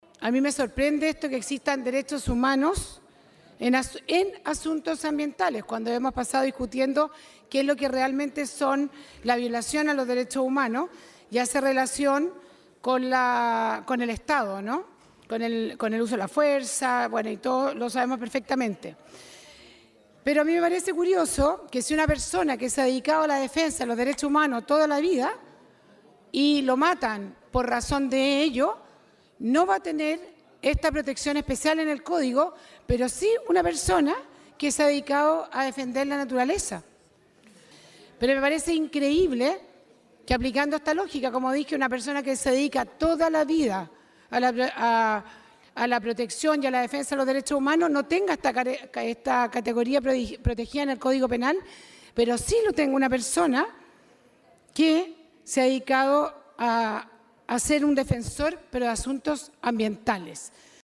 Desde la oposición, la diputada de Renovación Nacional Ximena Ossandón cuestionó que existan “derechos humanos en asuntos ambientales”, considerando que alguien que ha dedicado su vida a defender los derechos humanos no tenga la protección, en el Código Penal, que se busca para quienes defienden la naturaleza.